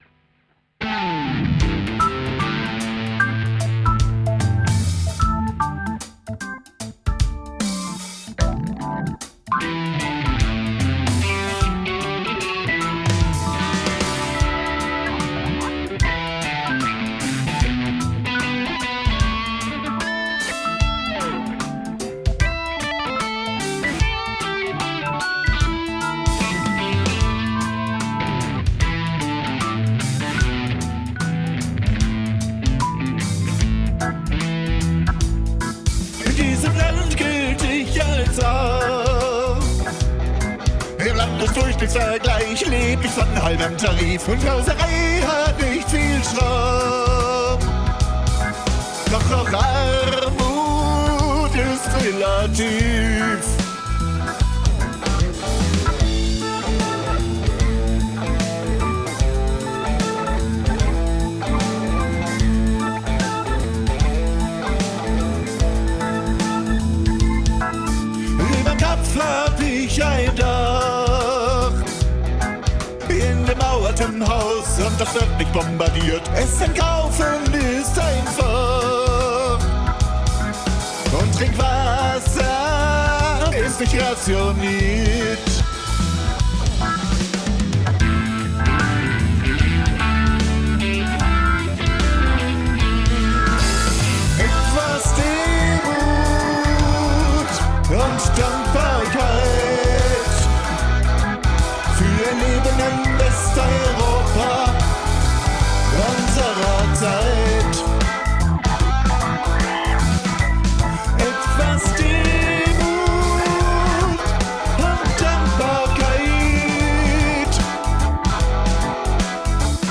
[rock]